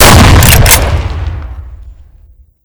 mp133_shoot1.ogg